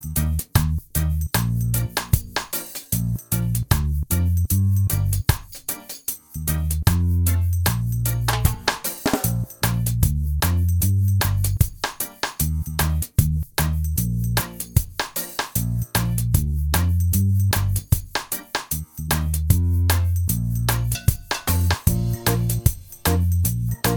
Minus Guitars Reggae 3:08 Buy £1.50